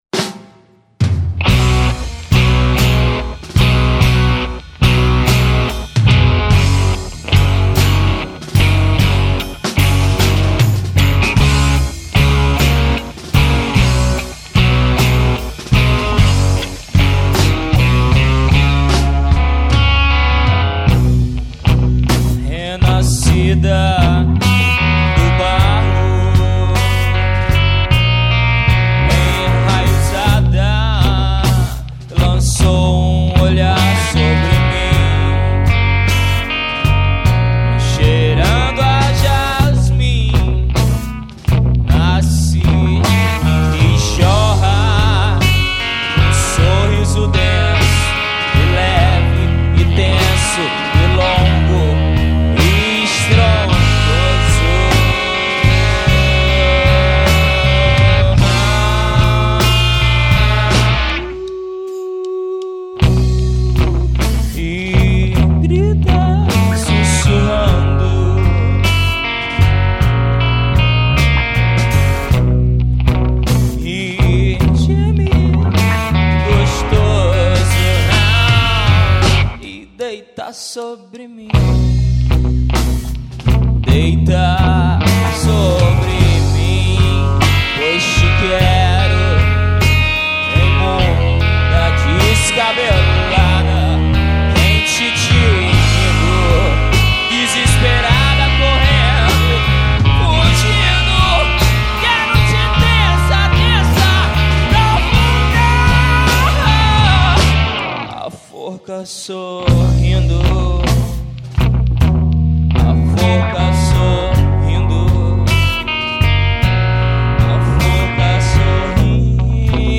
1713   04:26:00   Faixa:     Rock Nacional